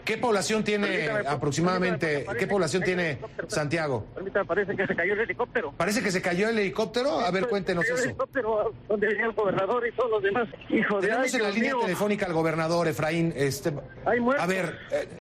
Así narraban en directo en la televisión mexicana el accidente del helicóptero